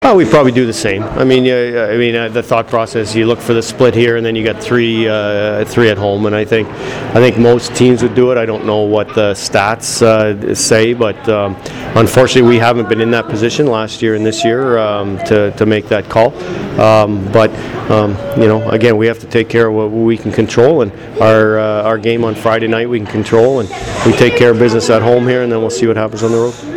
I attended practice and talked with plenty of guys on the team.